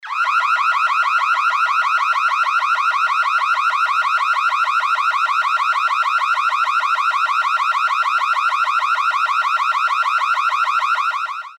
громкие
пугающие
Сирена
тревога
Так воет сигнализация, если совершается ограбление